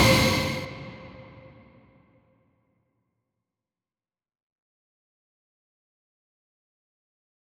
MDMV3 - Hit 10.wav